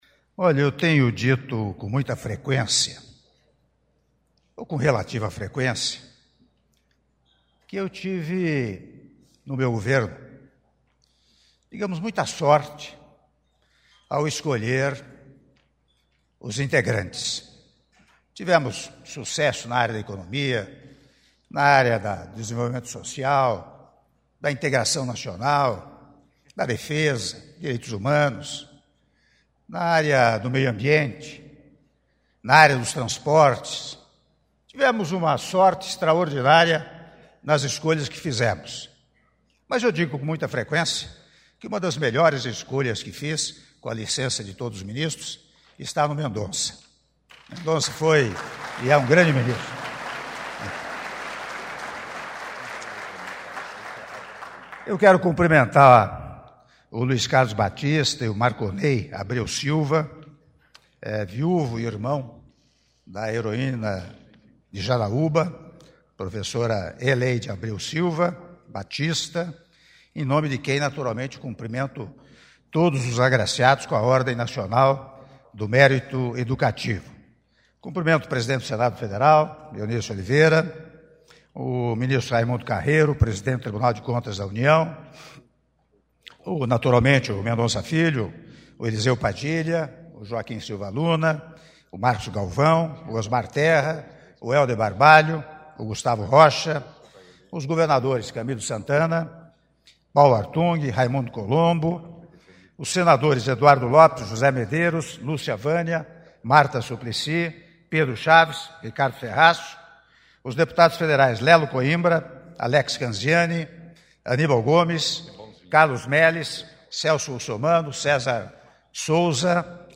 Áudio do discurso do Presidente da República, Michel Temer, durante Cerimônia de Entrega das Insígnias da Ordem Nacional do Mérito, in memoriam,
Cerimônia de Imposição de Insígnias da Ordem Nacional do Mérito Educativo